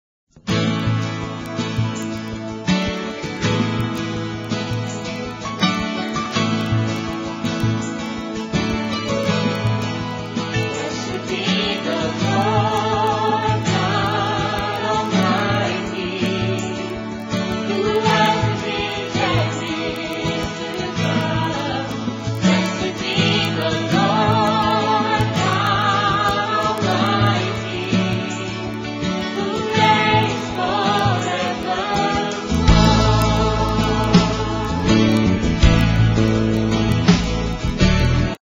4 tracks WITH GUIDE VOCALS